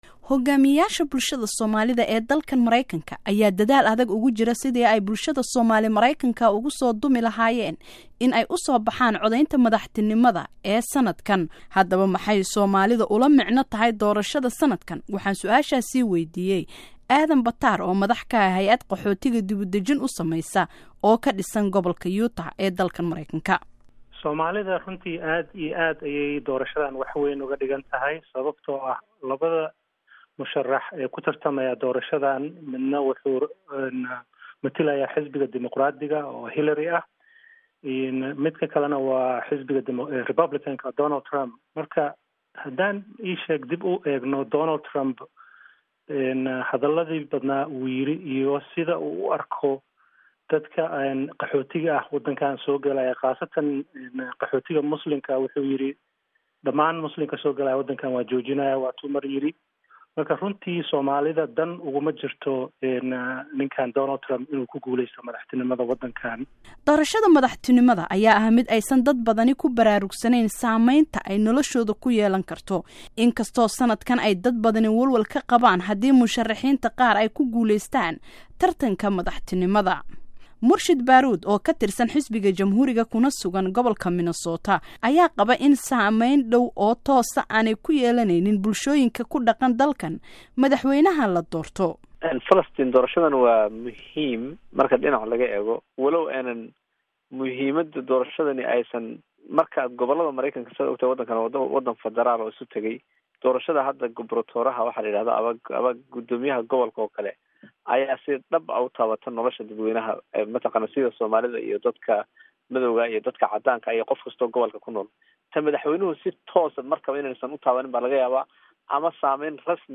Warbixin Doorashada Mareykanka iyo Doorka Soomaalida